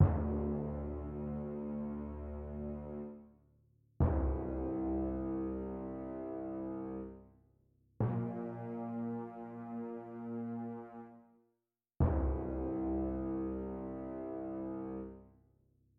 描述：铜管乐器部分1
Tag: 120 bpm Orchestral Loops Fx Loops 2.69 MB wav Key : Unknown